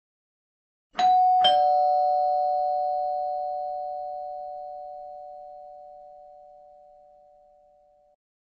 doorbell.mp3